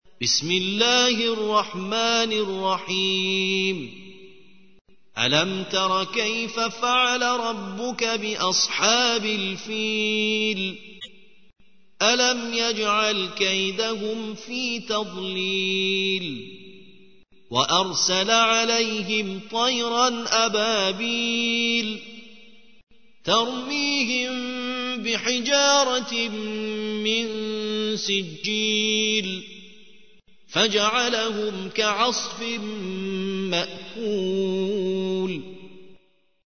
ترتیل